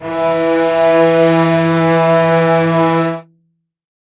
string.wav